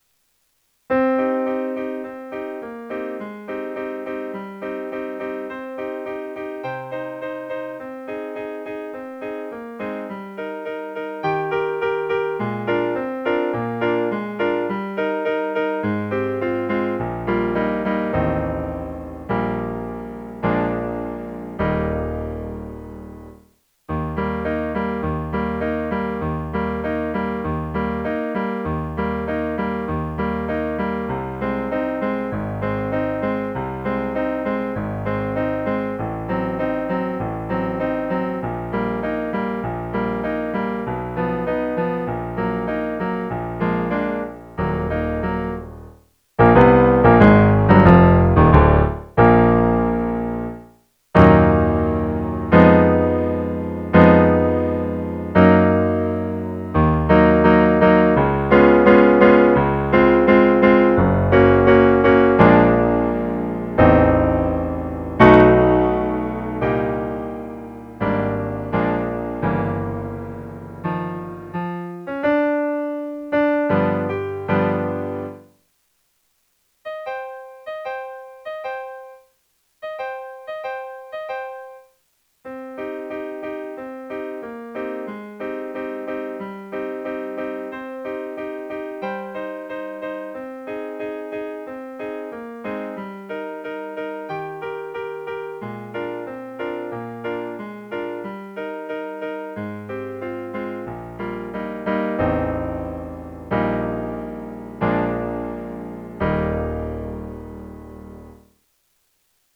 ''Still klingt das Glocklein durch Felder'' - 반주